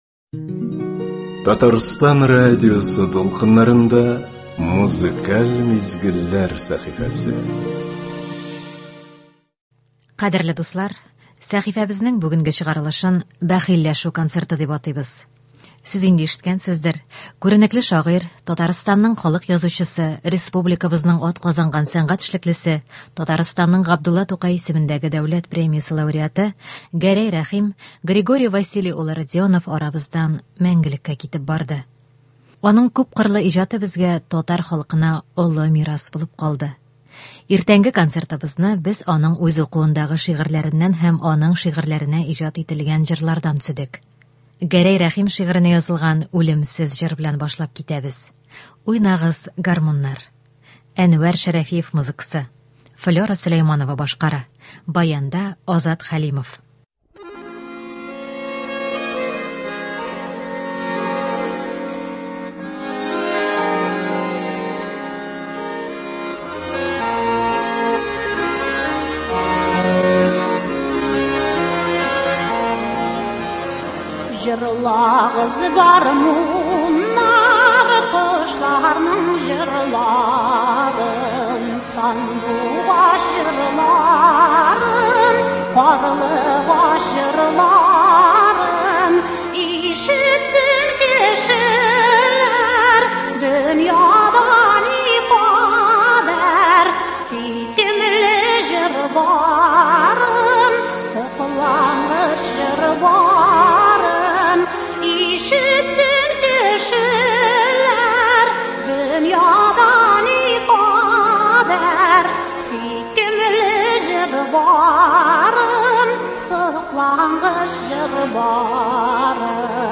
Иртәнге концертыбызны без аның үз укуындагы шигырьләреннән һәм аның шигырьләренә иҗат ителгән җырлардан төзедек.